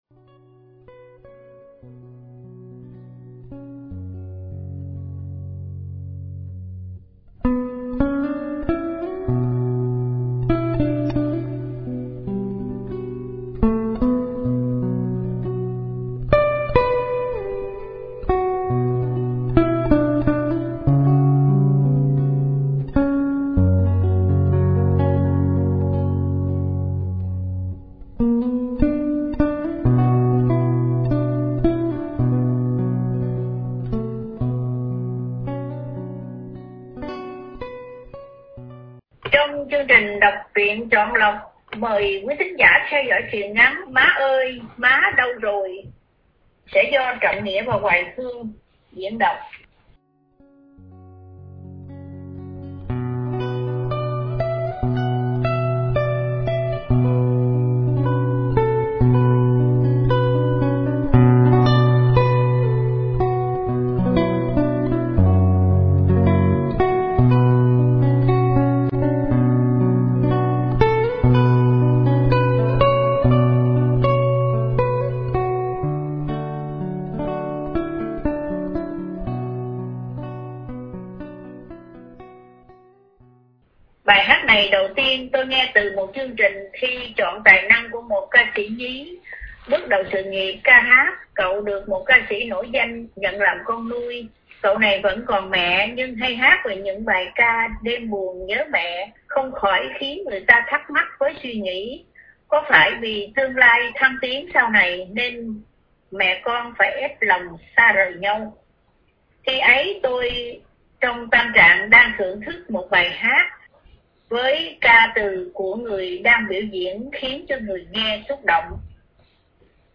Đọc Truyện Chọn Lọc – Truyện Ngắn ” Má Oi !…Má Đâu Rồi !” – Tác Giả Cỏ Biển – Radio Tiếng Nước Tôi San Diego